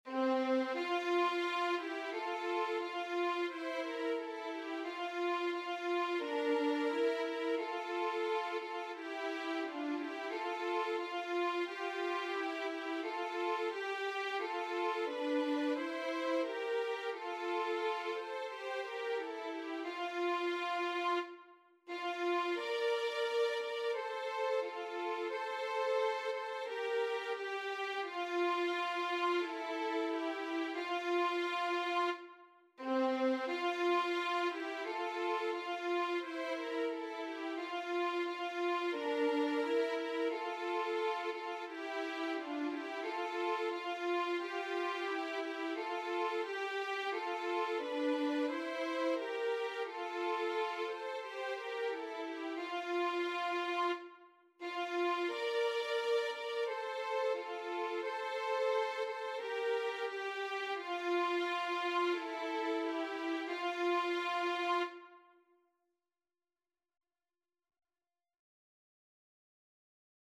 Title: Dos céus Rainha, jubilai Composer: Inocêncio Engelke Lyricist: Number of voices: 1v Voicing: Unison Genre: Sacred, Sacred song
Language: Portuguese Instruments: Organ